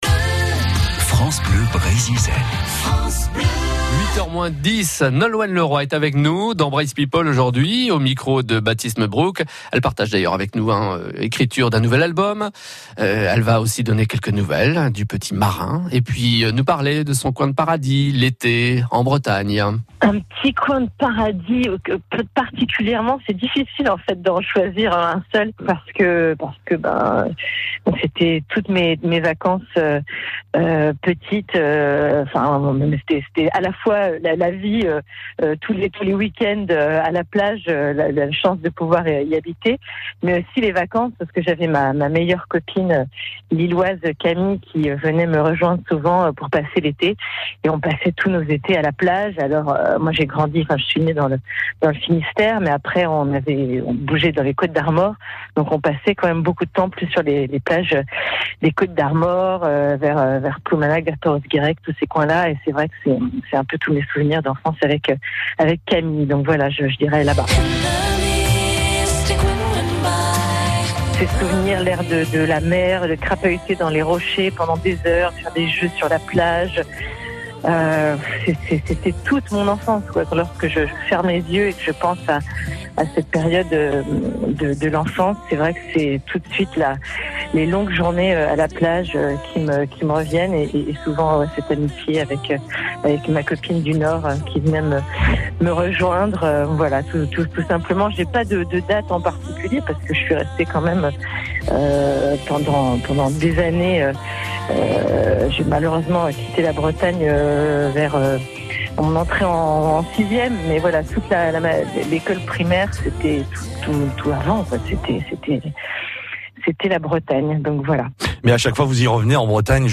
a passé un coup de téléphone à la célèbre Nolwenn Leroy qui raconte des souvenirs d'été en Bretagne